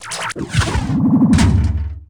thunk.ogg